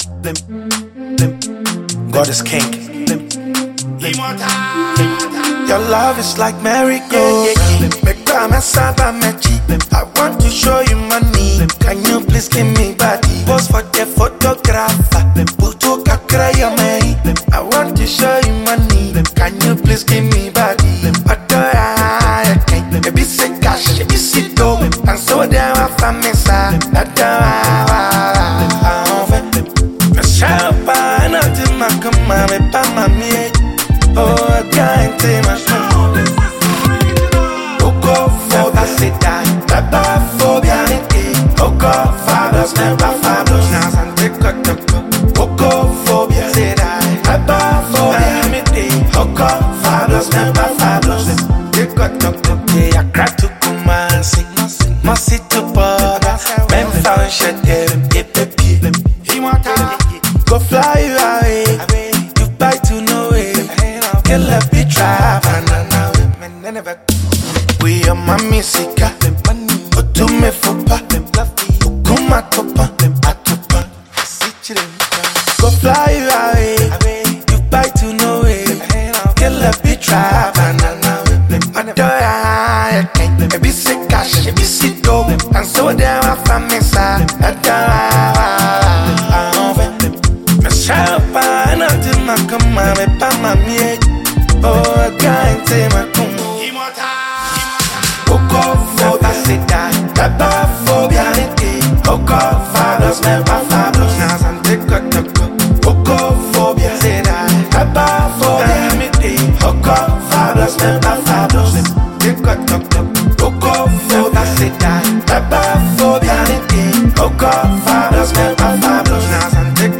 Ghanaian rap sensation